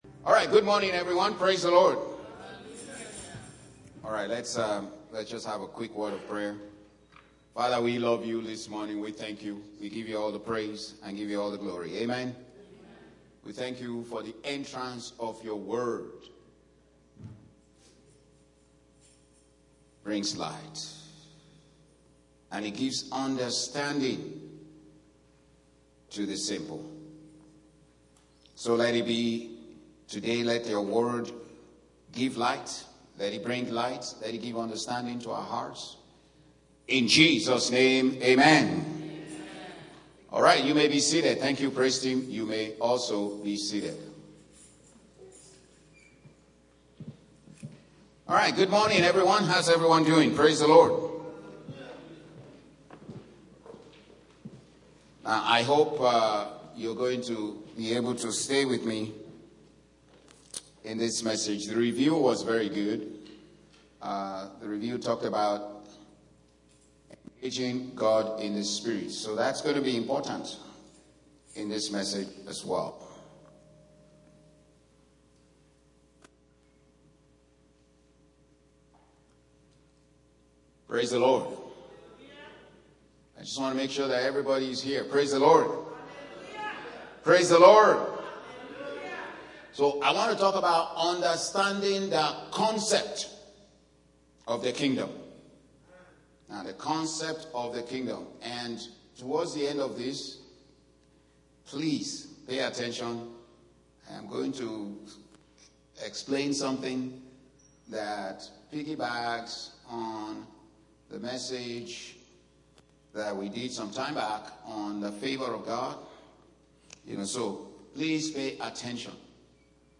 Audio Sermon | GMI Church